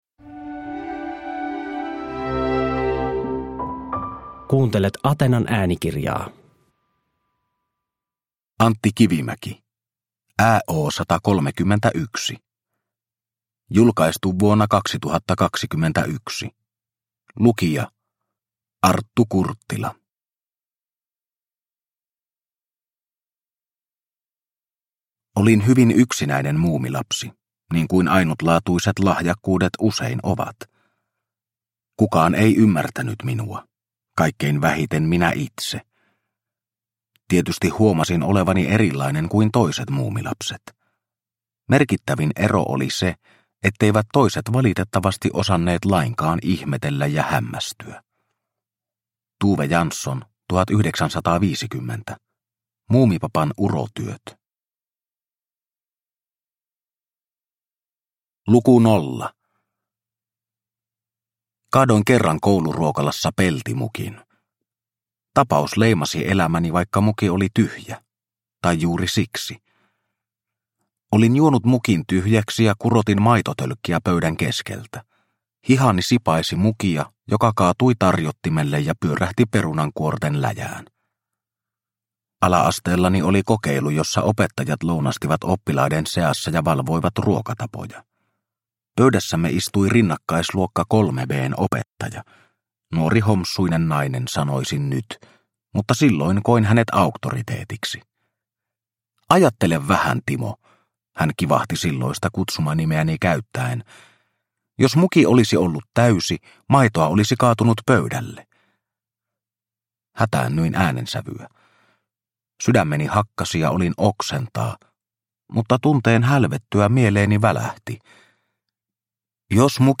ÄO 131 – Ljudbok – Laddas ner